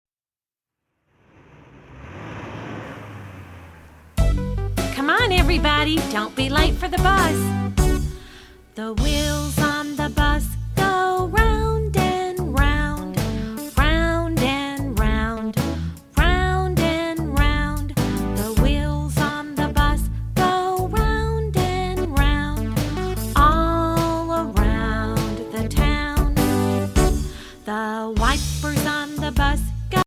children's favorite play-songs